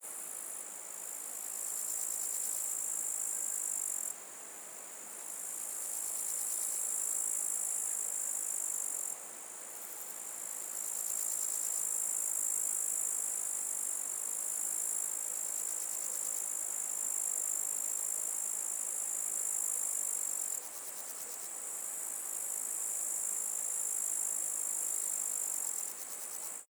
Звуки кузнечиков
Шепот летнего вечера: симфония кузнечиков в поле